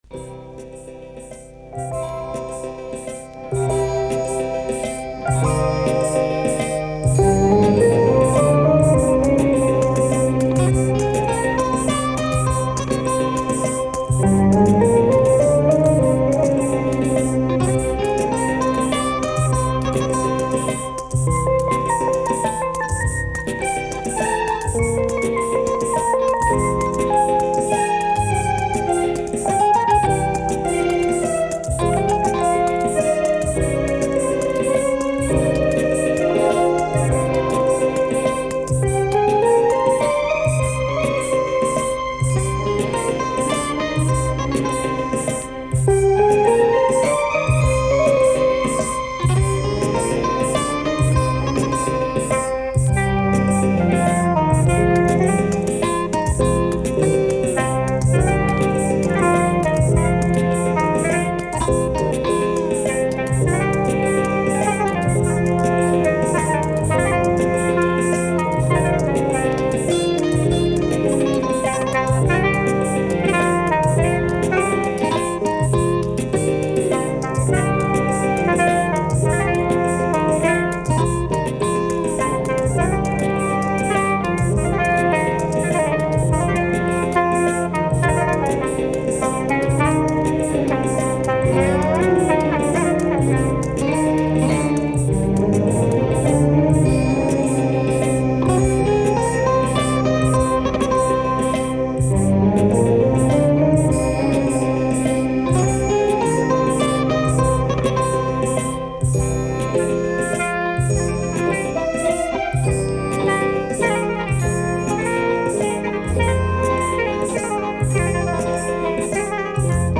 Eerie synth oriental or acid organ Arabic groove !